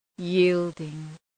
Προφορά
{‘ji:ldıŋ}